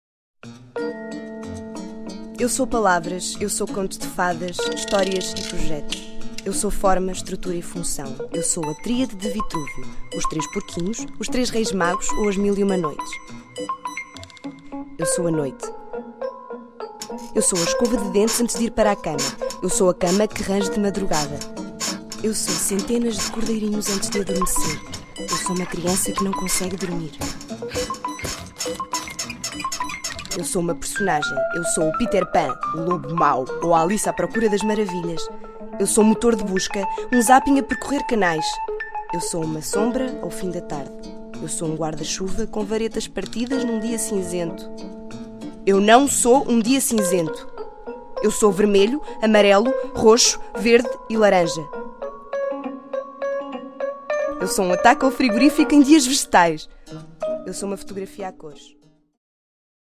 Voix-off - doublage, narration, publicité, industrie, e-learning.
Sprechprobe: Sonstiges (Muttersprache):